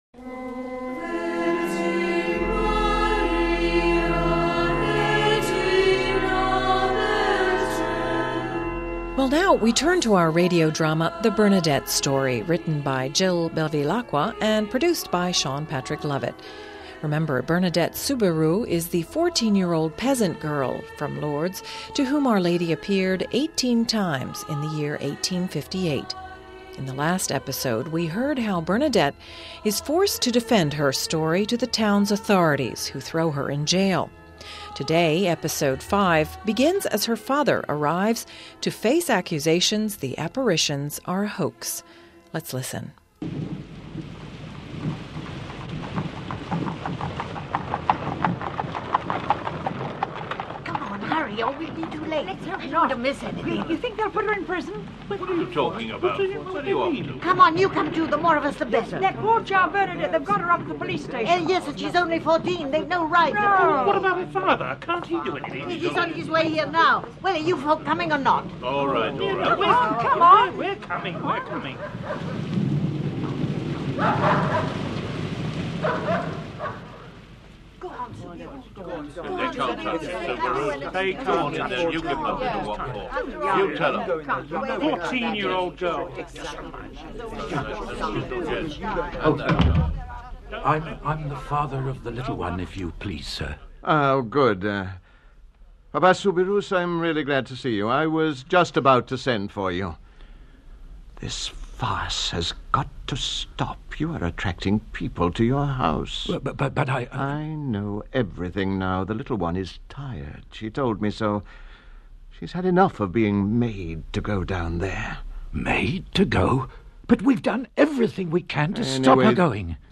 radio drama